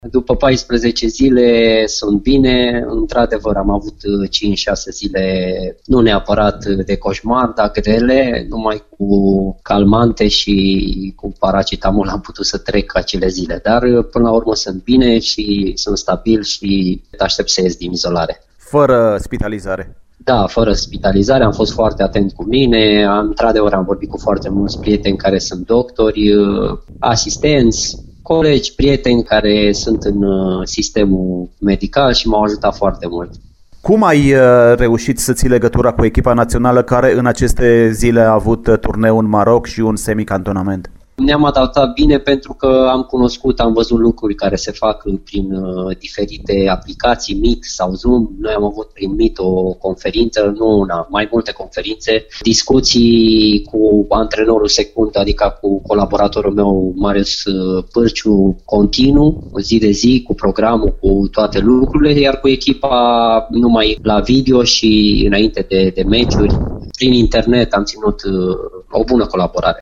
Cărășeanul a vorbit pentru Radio Timișoara despre această perioadă dificilă: